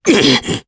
Crow-Vox_Damage_jp_02.wav